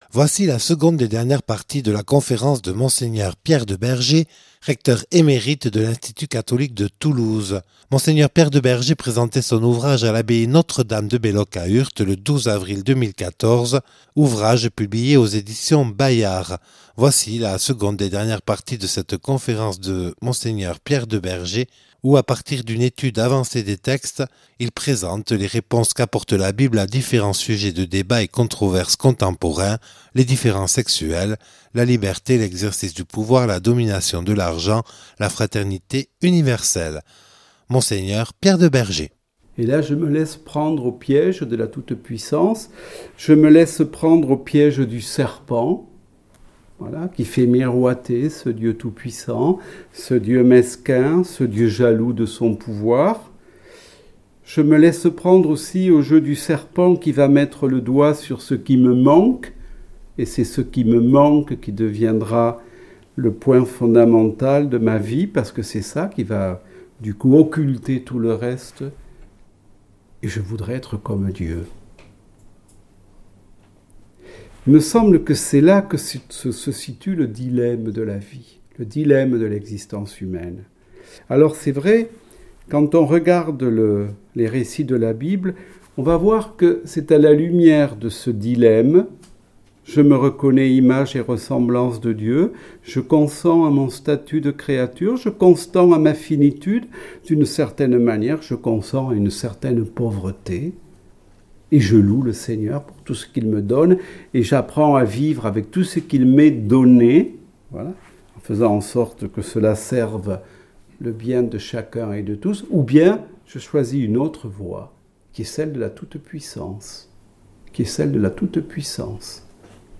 Conférence
(Enregistré le 12/04/2014 à l’abbaye Notre Dame de Belloc à Urt).